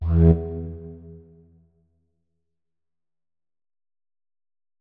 46265b6fcc Divergent / mods / Hideout Furniture / gamedata / sounds / interface / keyboard / flute / notes-16.ogg 30 KiB (Stored with Git LFS) Raw History